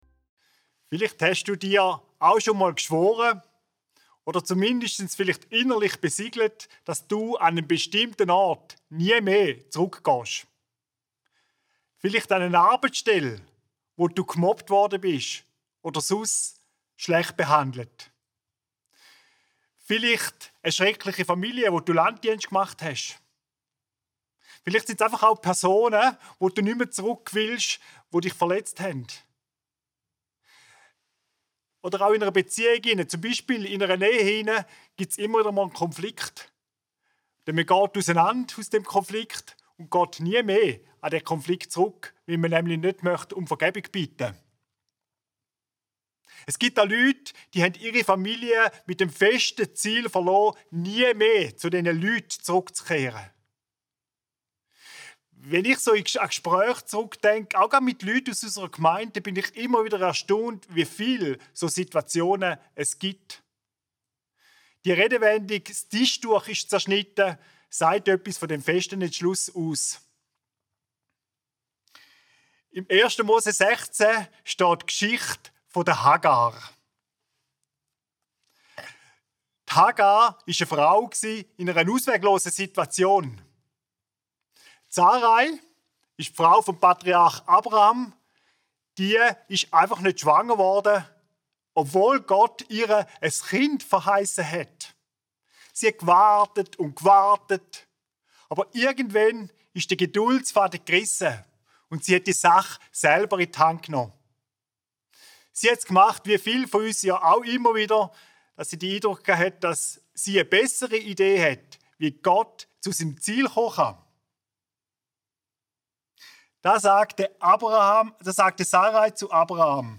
Hagar – du bist (an)gesehen - seetal chile Predigten